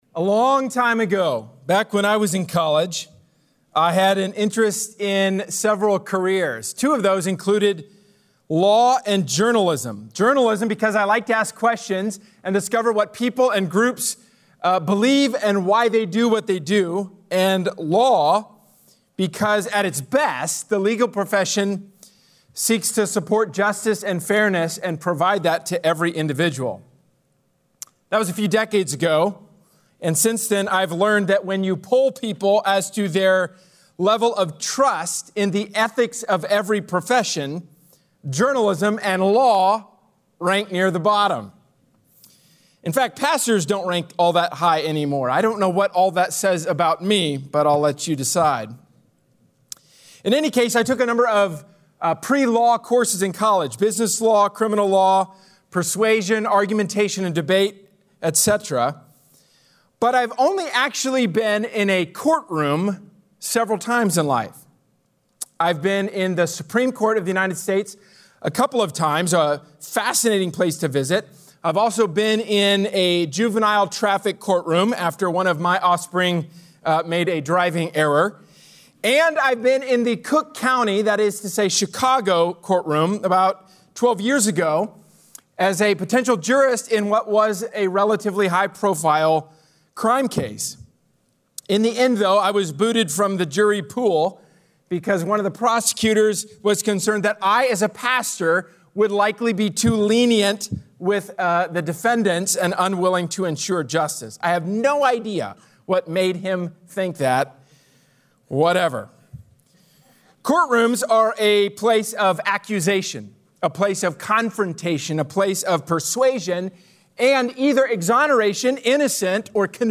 A sermon from the series "James: Faith/Works."